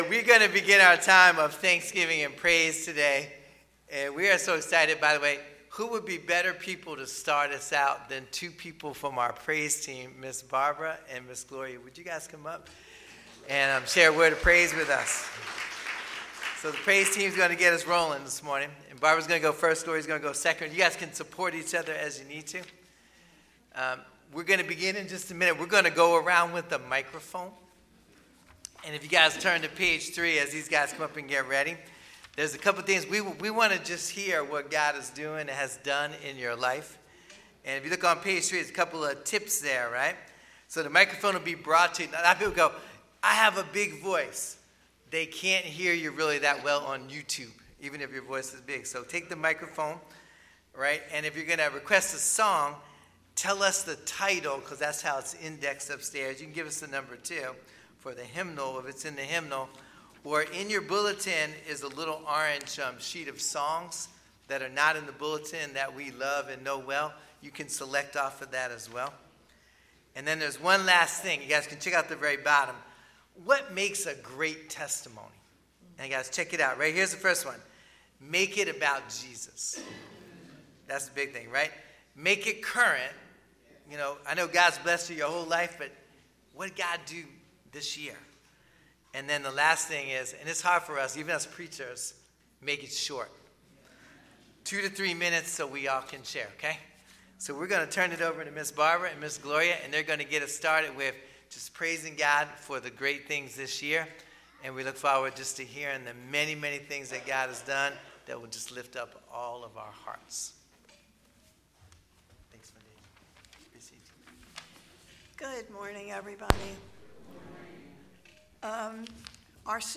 Sermons | First Baptist Church of Willingboro, NJ